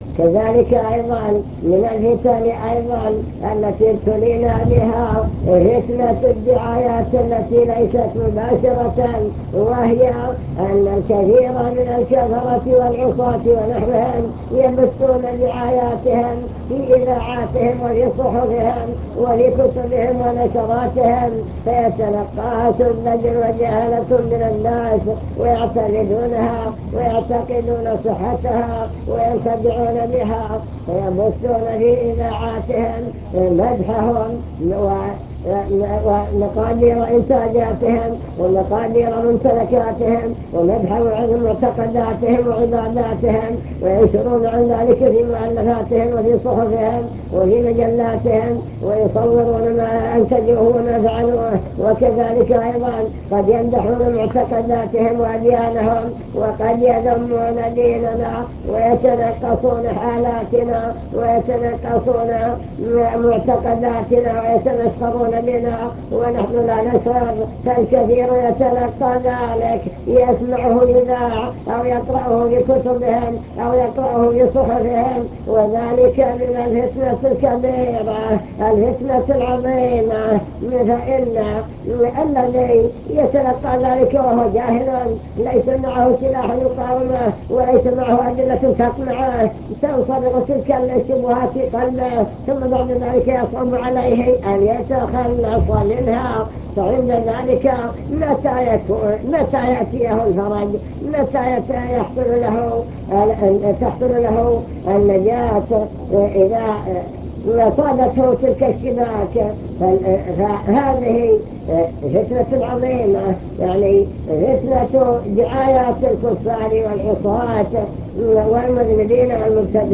المكتبة الصوتية  تسجيلات - محاضرات ودروس  محاضرة في فتن هذا الزمان ومقاومتها